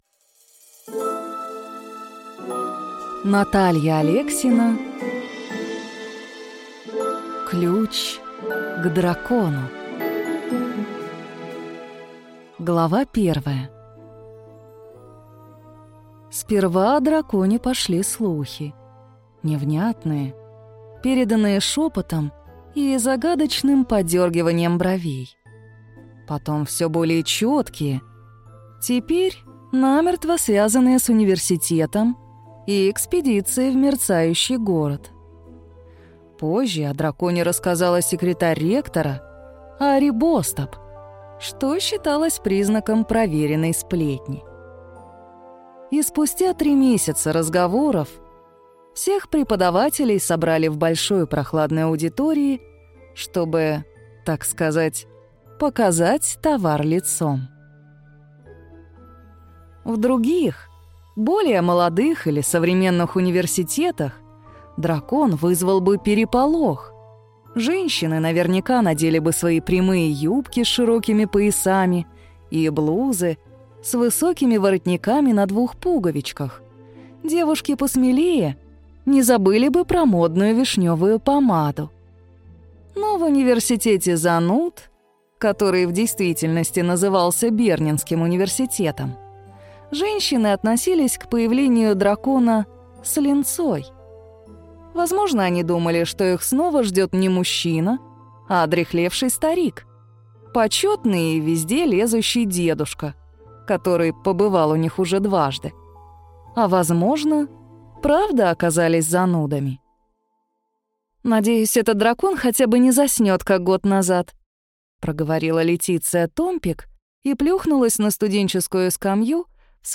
Аудиокнига Ключ к дракону | Библиотека аудиокниг